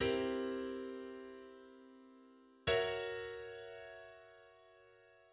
Tritone_substitution.mid.mp3